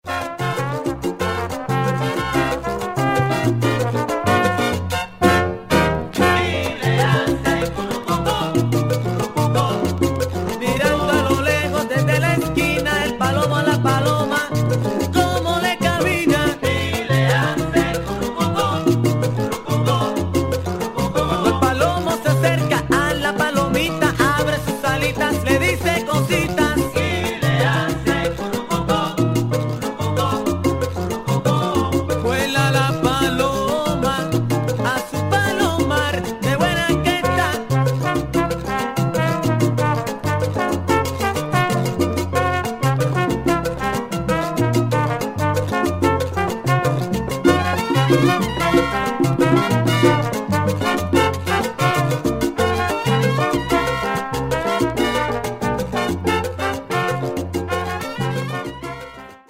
Colombian salsa and cumbias